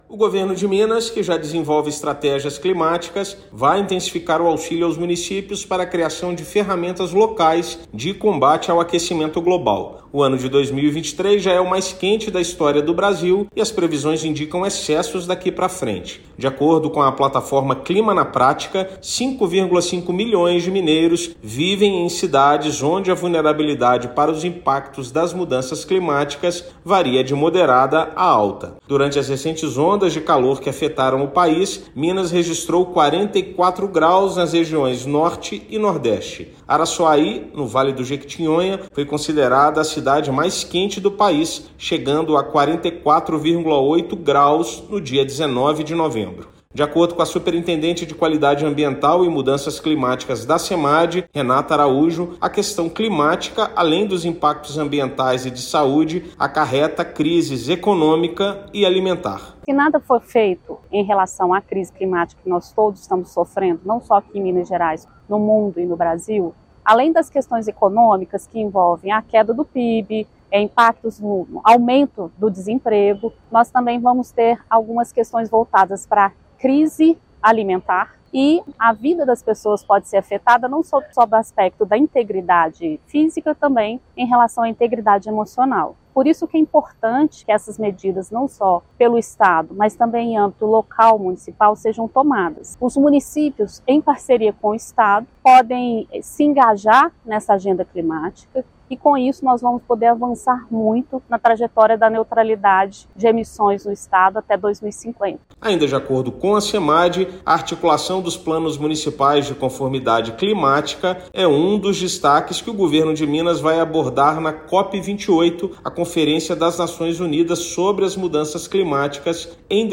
Mais de 50% dos municípios mineiros estão vulneráveis aos efeitos climáticos; para conter esses efeitos, Estado intensifica ações para auxiliar os municípios na construção de estratégias locais. Ouça matéria de rádio.